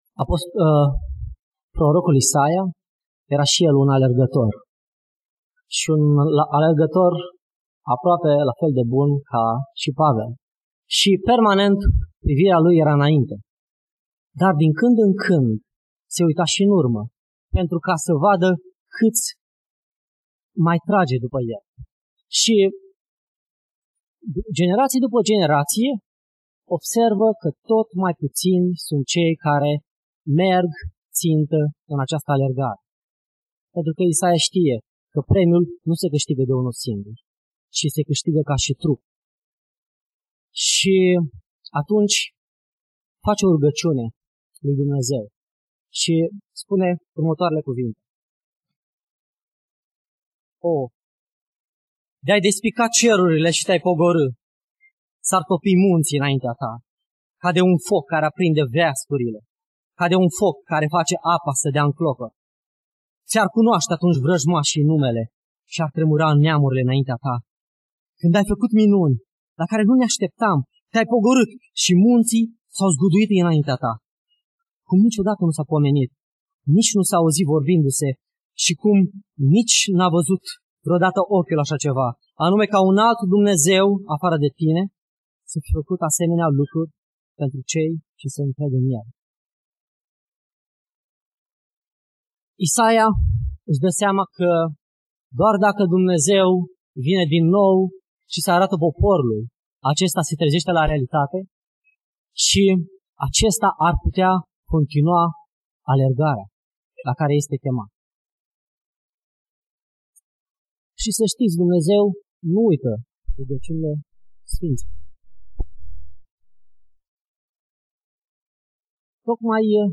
Predica Exegeza - Matei 8